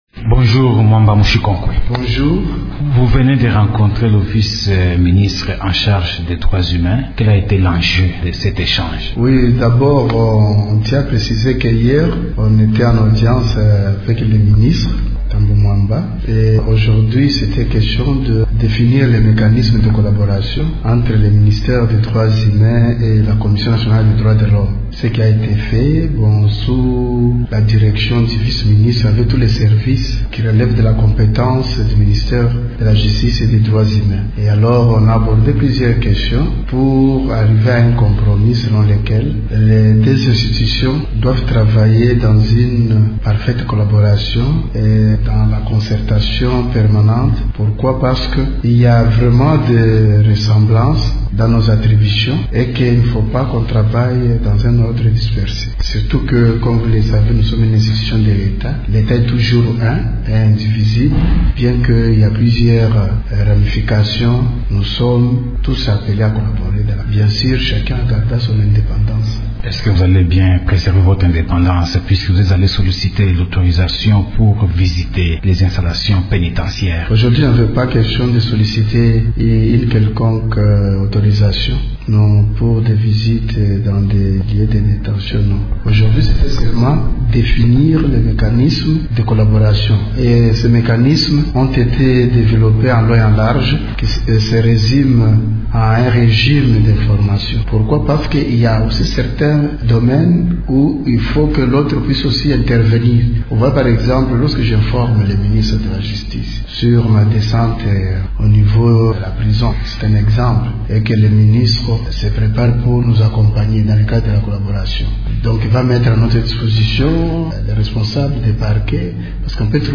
« Nous sommes les conseillers de l’Etat congolais en cette matière », explique le président de la CNDH, Mwamba Mushinkokwe Mwamus.